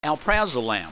Pronunciation
(al PRAY zoe lam)